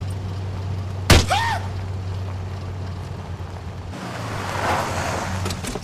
yelps hits and screams with engine.ogg
Original creative-commons licensed sounds for DJ's and music producers, recorded with high quality studio microphones.
yelps_hits_and_screams_withj_engine_m5n.mp3